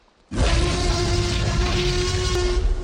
Download Free Trex Sound Effects
Trex